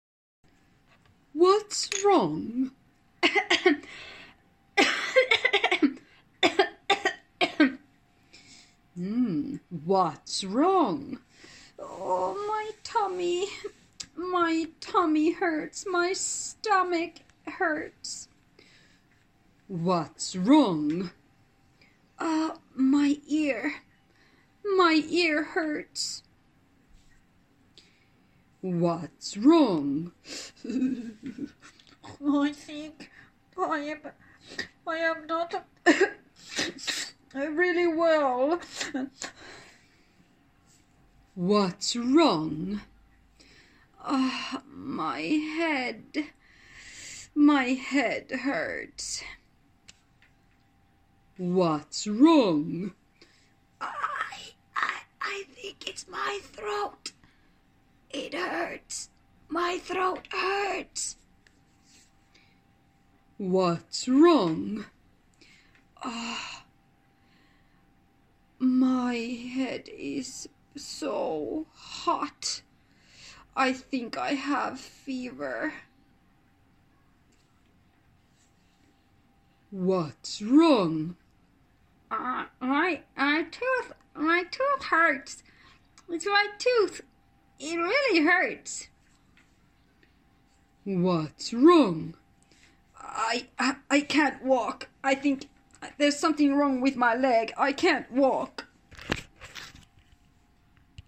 Kuulit yhdeksän potilasta ääninauhalta.
Nine patients.